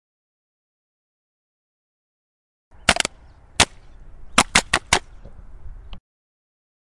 酒后驾车打酒瓶
描述：用拳头打水瓶
Tag: 塑料瓶 命中 容器